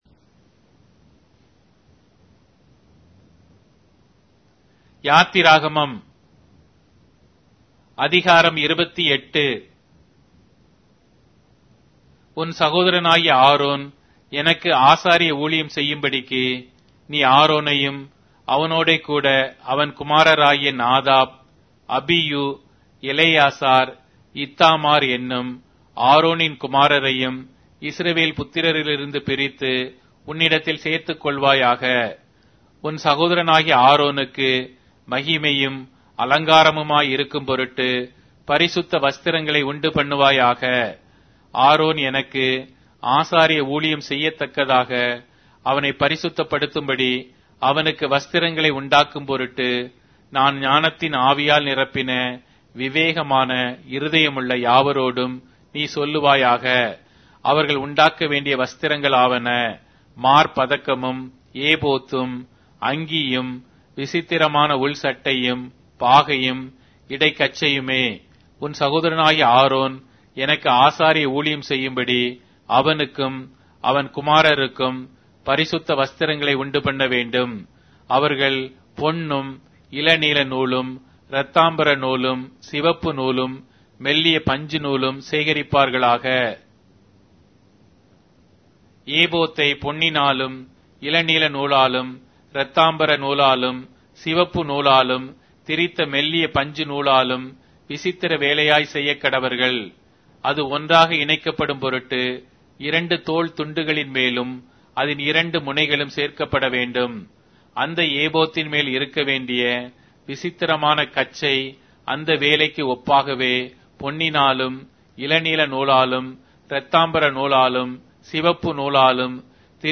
Tamil Audio Bible - Exodus 12 in Ervte bible version